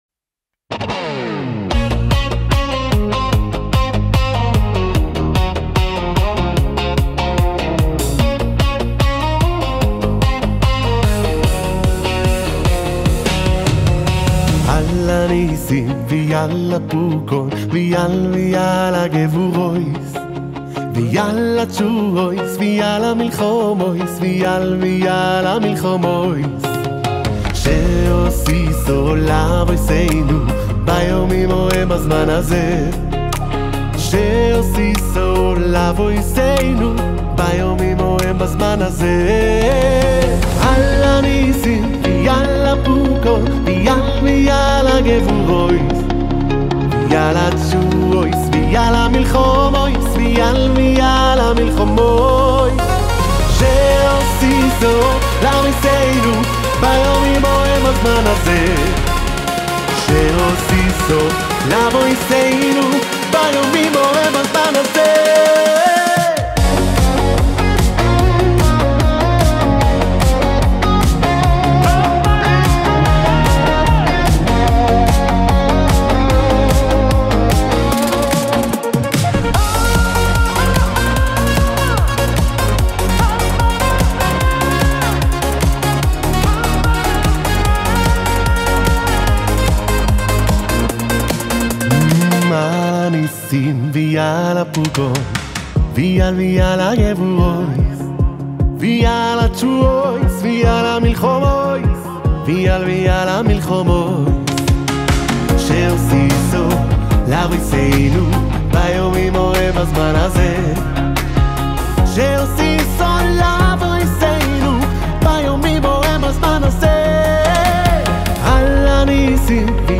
בלהיט חגיגי ולחן מקורי